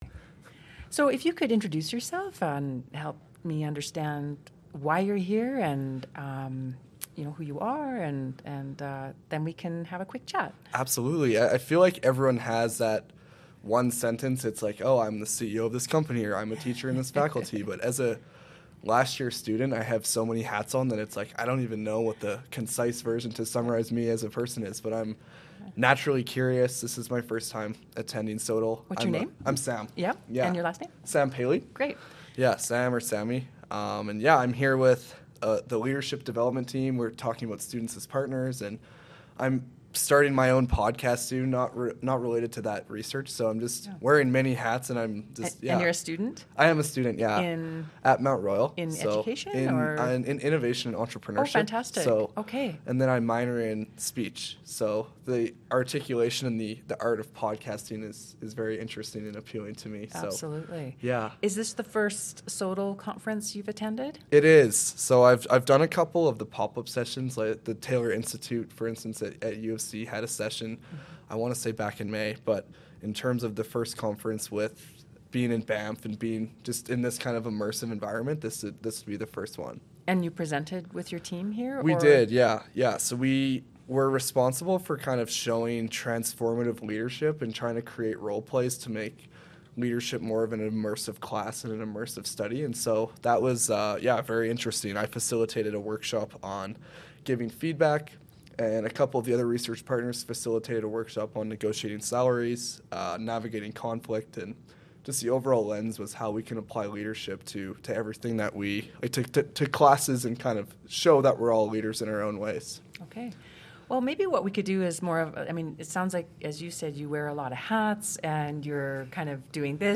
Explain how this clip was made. The SOTL in 60+ podcast series consisted of ten episodes recorded during the 2024 Symposium for Scholarship of Teaching and Learning November 7-9, 2024 in Banff, Alberta, Canada.